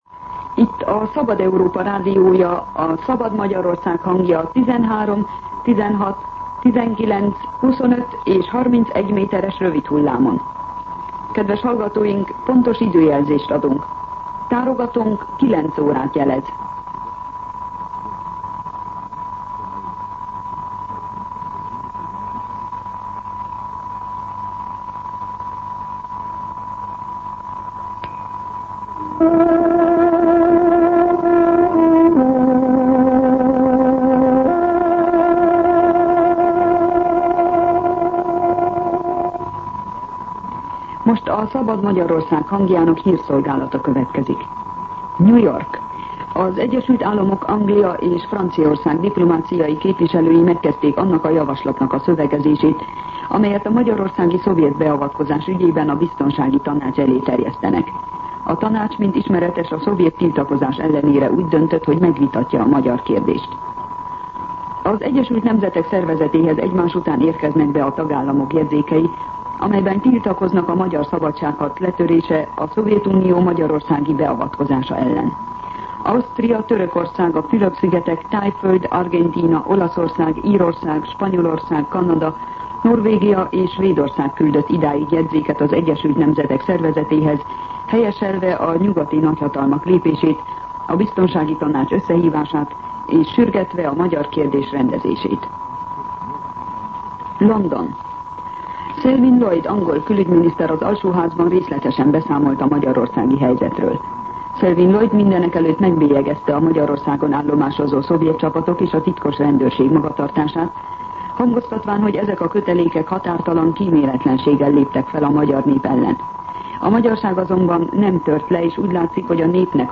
09:00 óra. Hírszolgálat